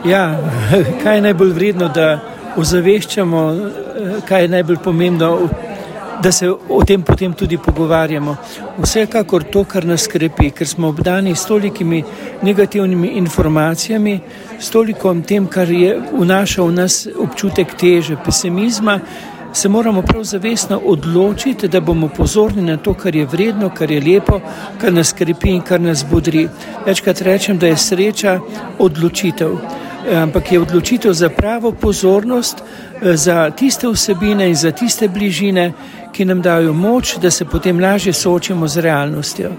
Sinoči je bil gost v slovenjegraški knjižnici: